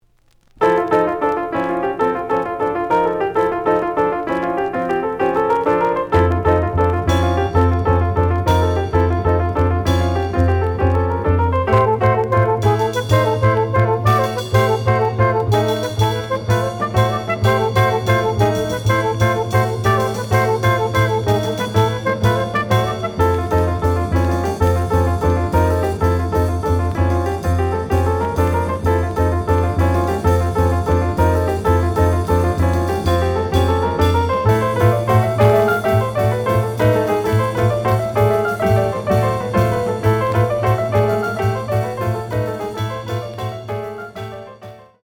The audio sample is recorded from the actual item.
●Genre: Cool Jazz